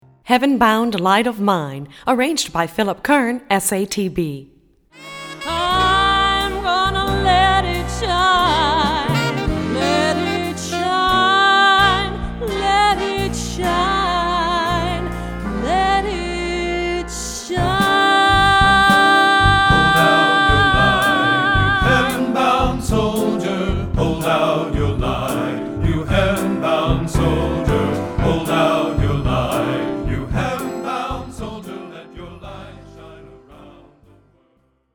Composer: Spiritual
Voicing: Accompaniment CD